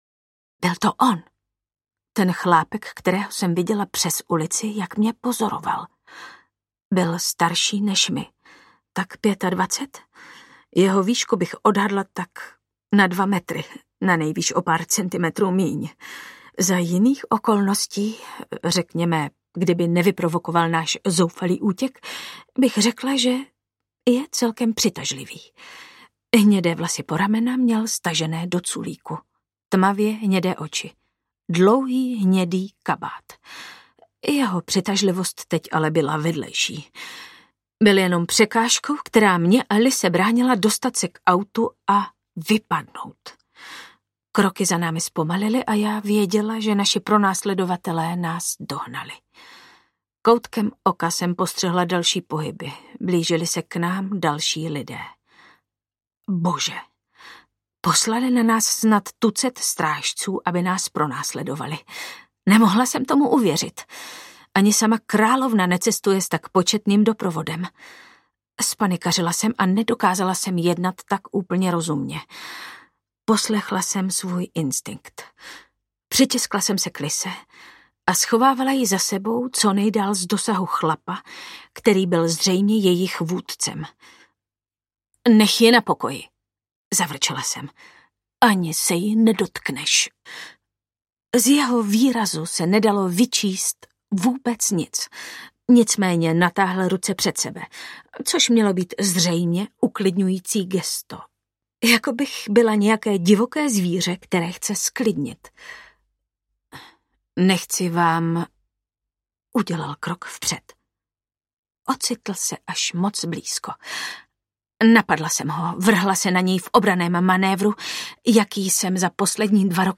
Vampýrská akademie audiokniha
Ukázka z knihy
Vyrobilo studio Soundguru.